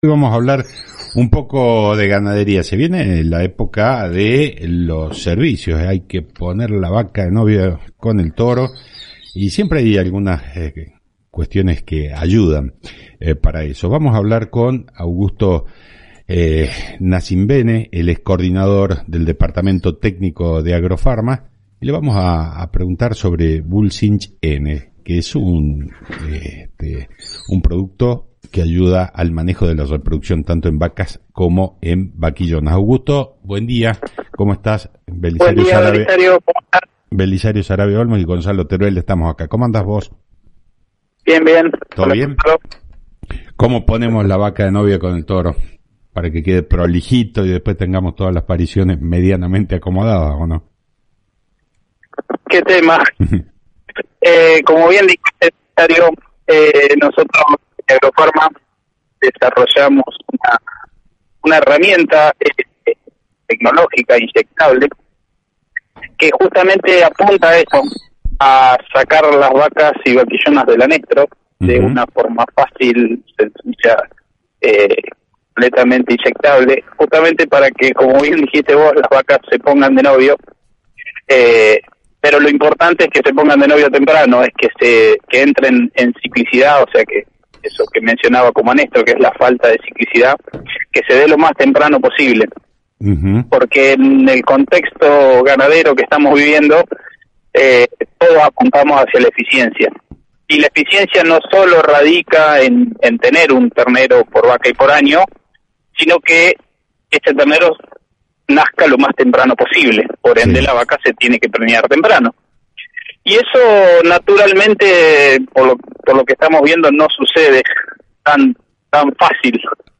en el programa Claves del Campo (Radio Salta AM 840)